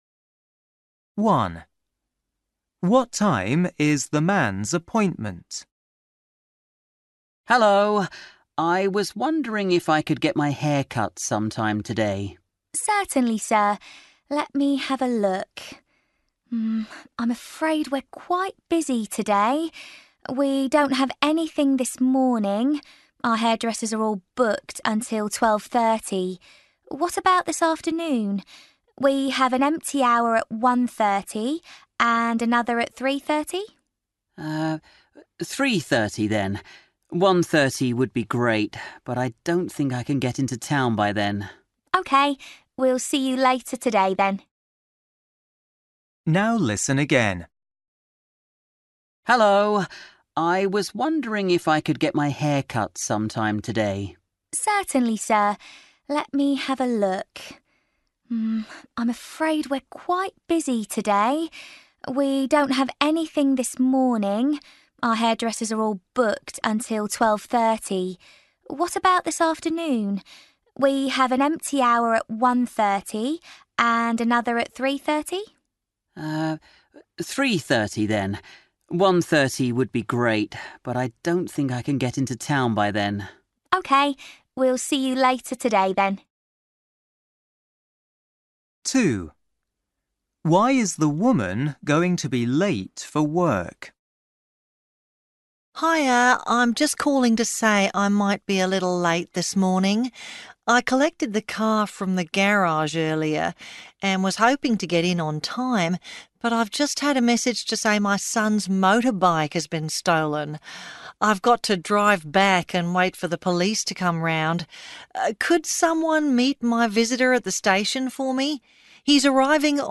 13   You will hear two friends talking about a local businesswoman.